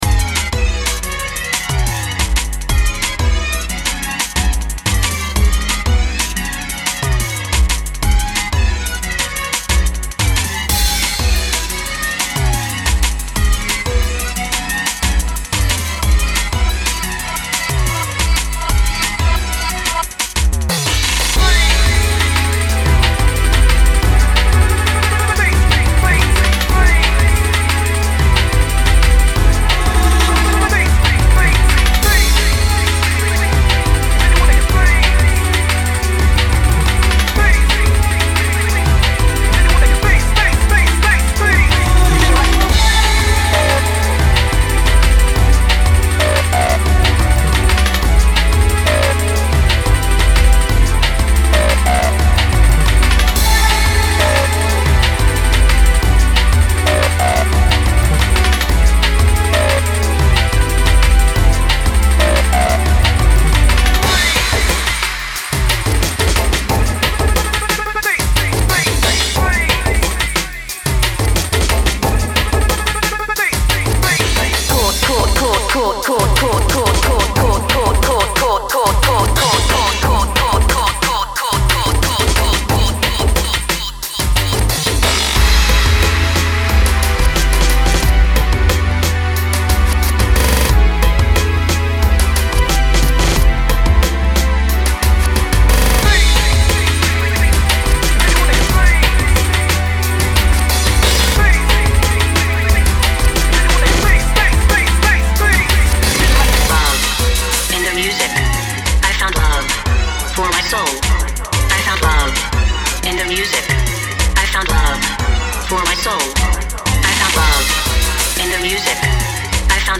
BPM90-180
Drum and bass isn't normally my style.